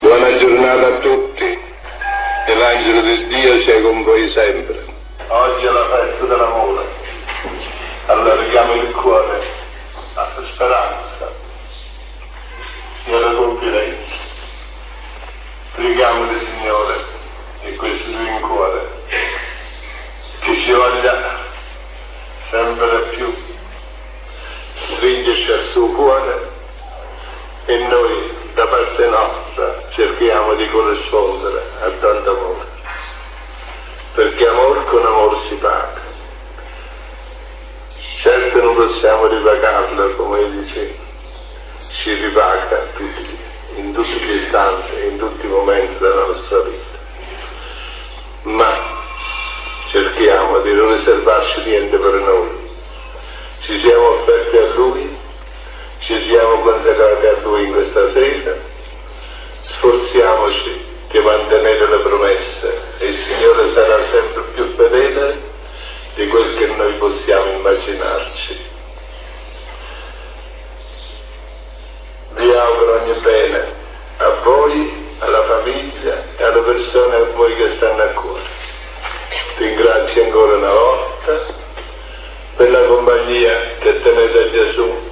Qui sotto trovate quattro files della voce di Padre Pio.
Preghiera del mattino (170 Kb)